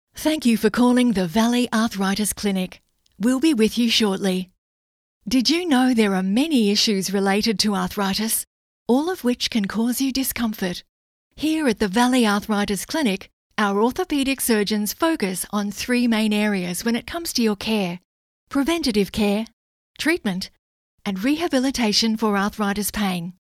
• On Hold
• Rode Procaster mic